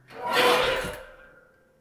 metal_creak8.wav